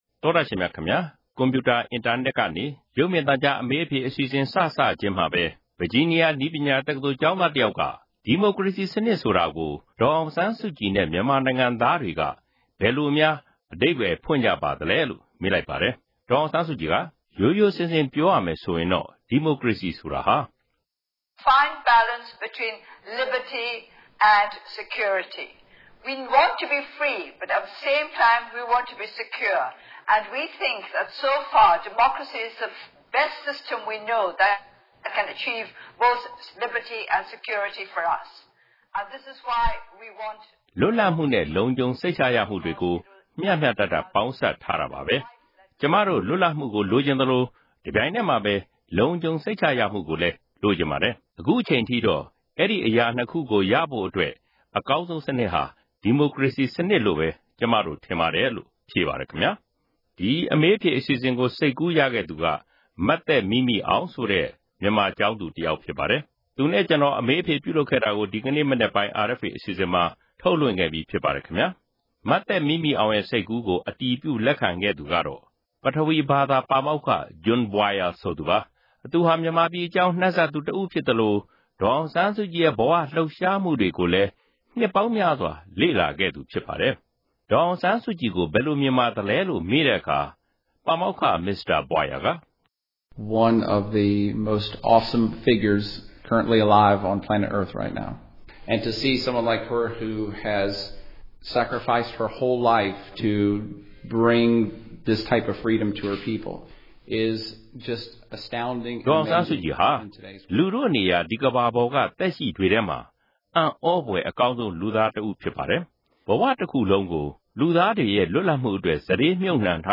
မြန်မာ့ဒီမိုကရေစီ ခေါင်းဆောင် ဒေါ်အောင်ဆန်းစုကြည်ဟာ ဒီကနေ့ မနက်ပိုင်းက အမေရိကန် ပြည်ထောင်စု ဗာဂျီးနီးယားပြည်နယ် စက်မှုတက္ကသိုလ် Virginia Tech က ကျောင်းသူ ကျောင်းသားတွေနဲ့ အင်တာနက် ဗီဒီယိုက တဆင့် မြန်မာ့ဒီမိုကရေစီရေးနဲ့ ပညာရေးကိစ္စတွေကို တိုက်ရိုက် ဆွေးနွေးခဲ့ပြီး၊ ကျောင်းသားတွေရဲ့ မေးခွန်းတွေကို ပြန်လည်ဖြေကြားခဲ့ပါတယ်။
အမေးအဖြေ (ပထမပိုင်း)။